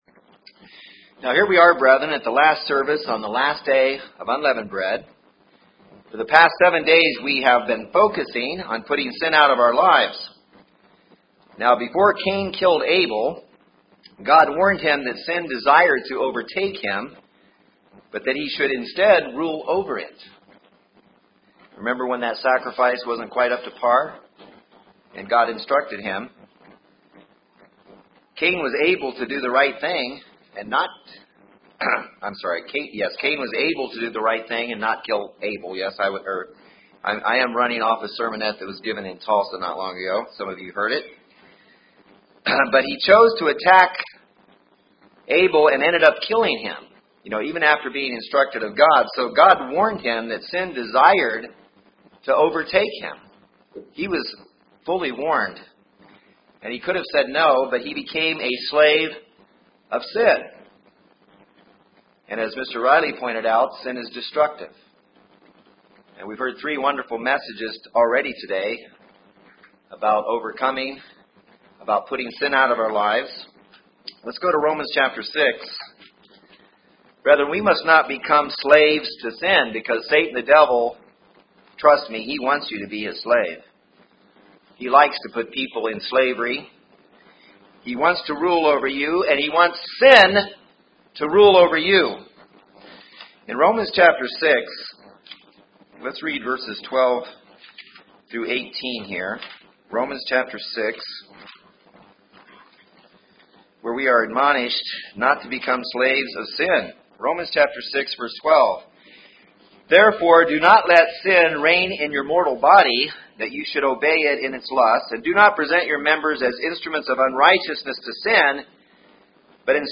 This sermon was given on the Last Day of Unleavened Bread.